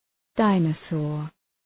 Προφορά
{‘daınə,sɔ:r}